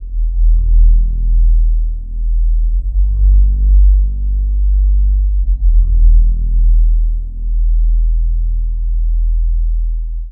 Bass 47.wav